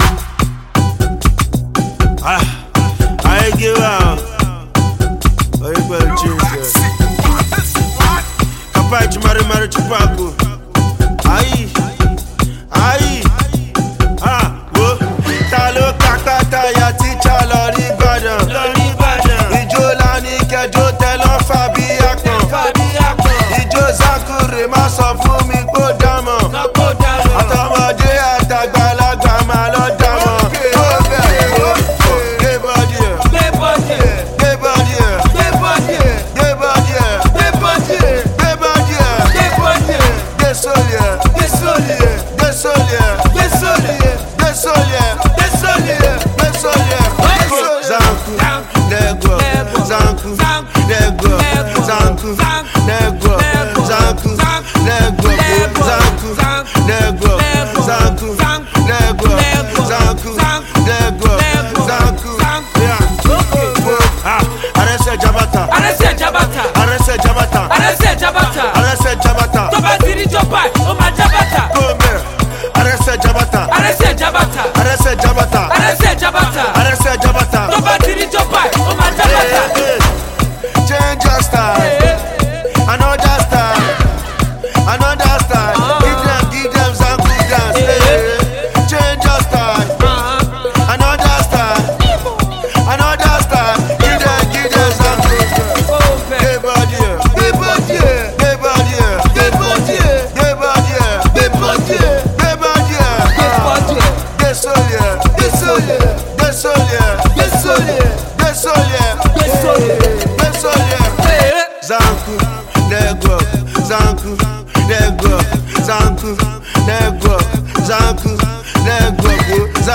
brand new H0t dance tune